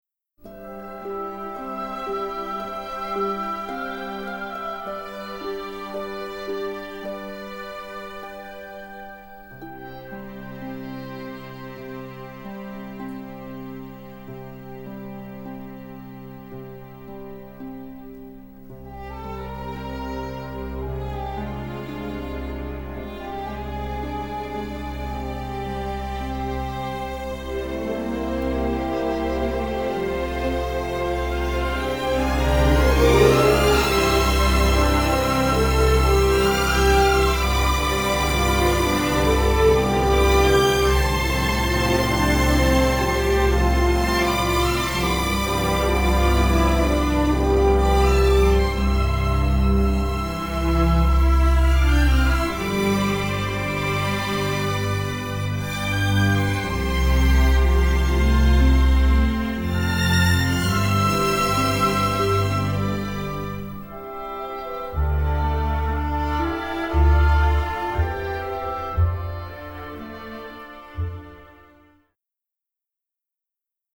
Everything has been remastered from superior master elements